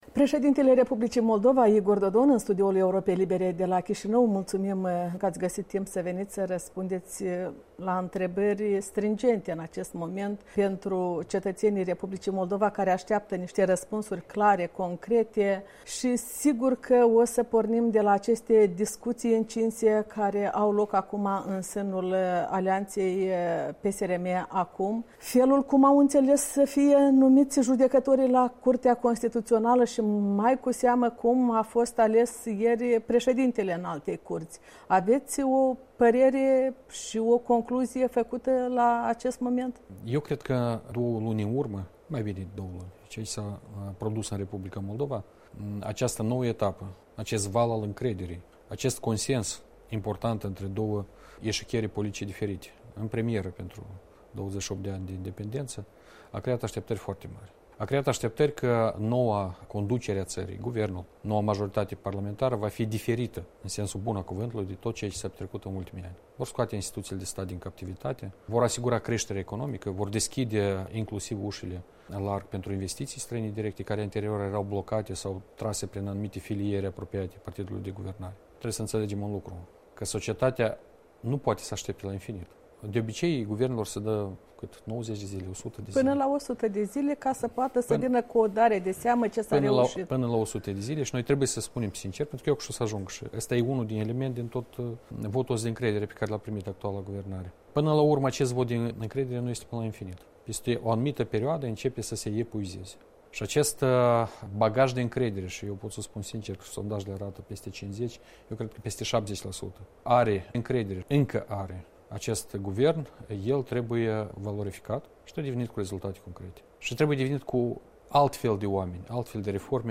Interviu cu președintele Republicii Moldova despre numirile recente în funcții cheie ale statului și disensiunile din coaliția de guvernare.
Interviu cu Igor Dodon